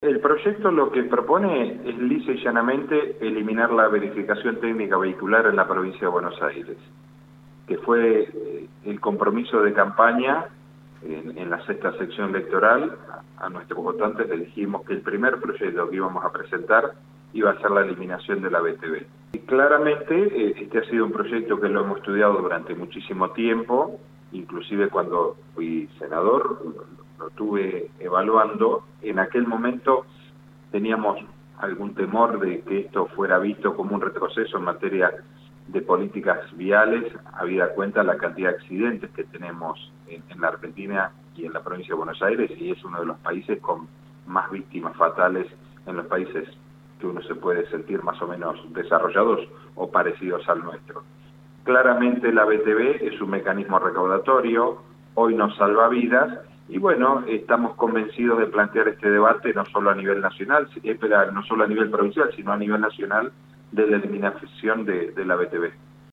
El diputado bonaerense por la Coalición Cívica, Andrés De Leo en conversación con LU24 hizo referencia al proyecto que presentara para eliminar la Verificación Técnica Vehicular (VTV) en la provincia de Buenos Aires.